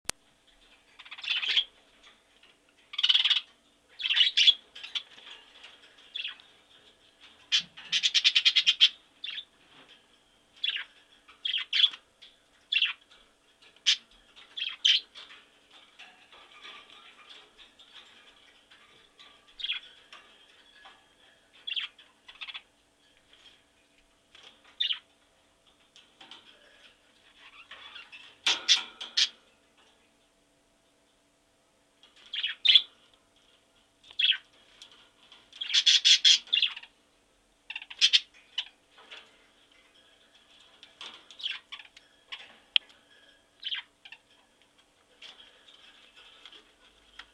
A flock of wavy parrots walking around
• Category: Wavy parrots